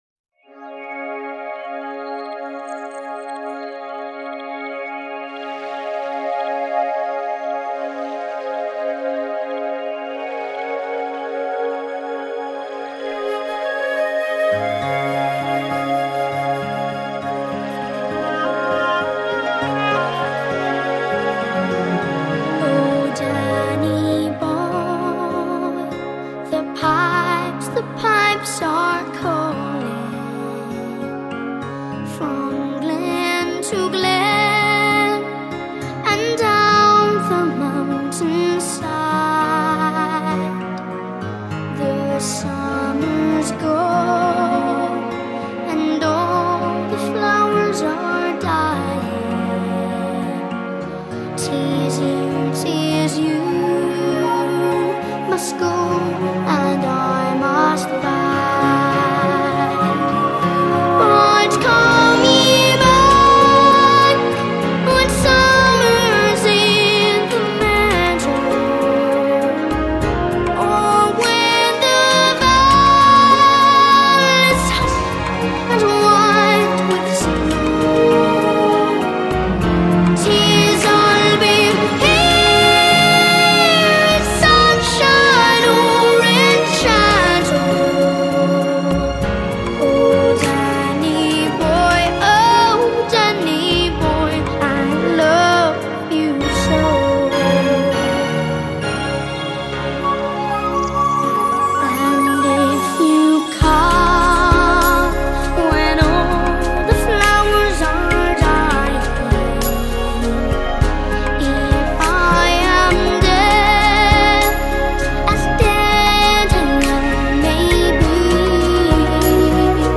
乐曲纯朴动人，充满温馨情调。